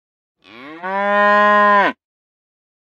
moo.ogg.mp3